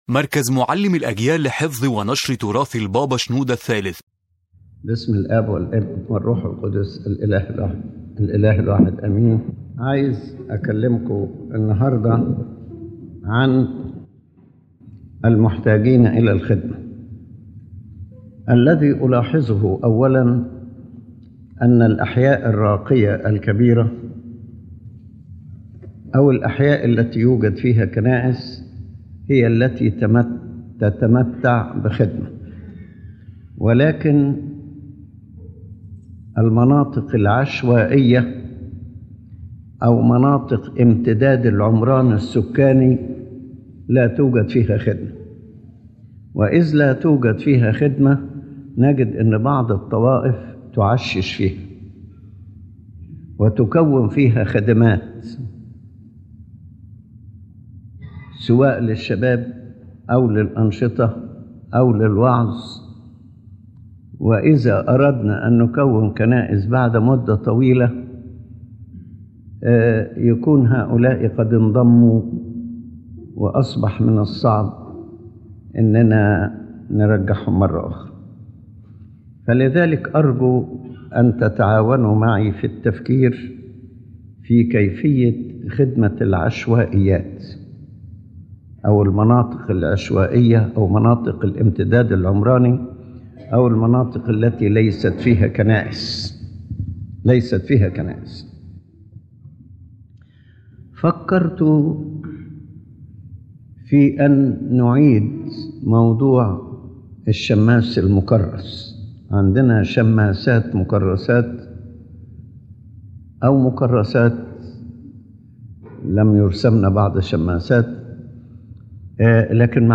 General Idea of the Lecture